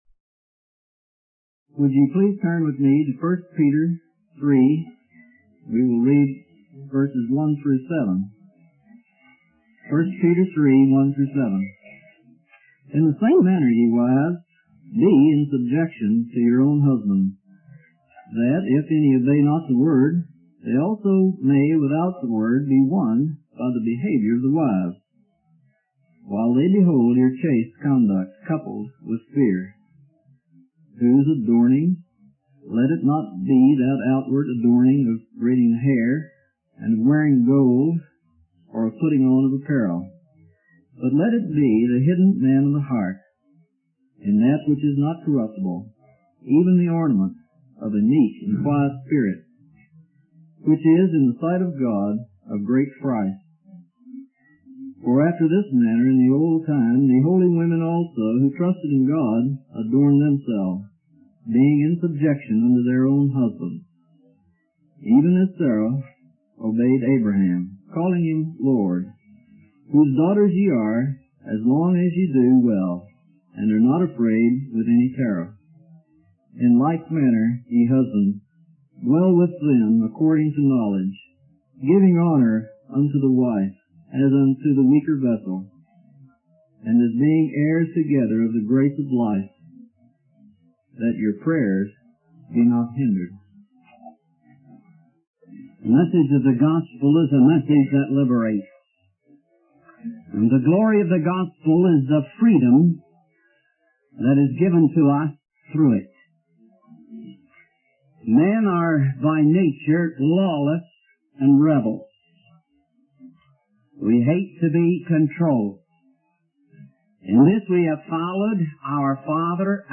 In this sermon, Peter teaches that an unsaved wife can win her husband to the Lord by being a model wife in every aspect of her life.